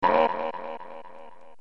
kaptain-king-rool-hurt.mp3